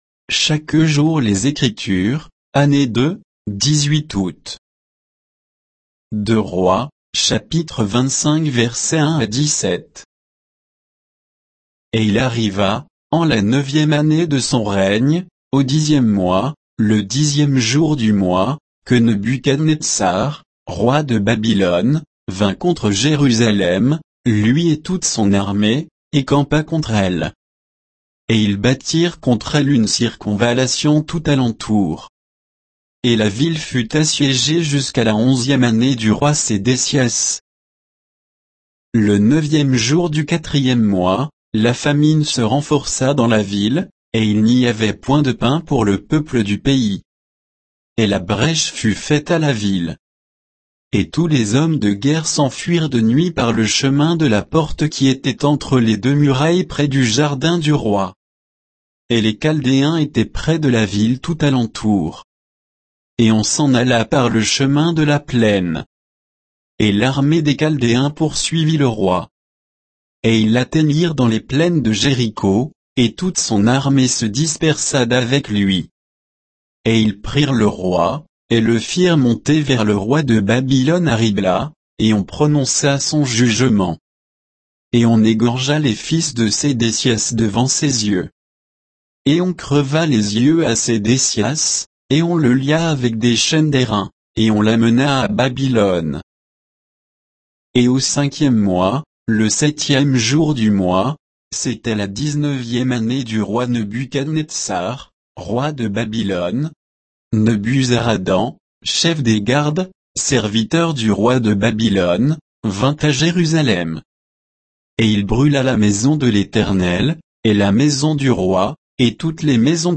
Méditation quoditienne de Chaque jour les Écritures sur 2 Rois 25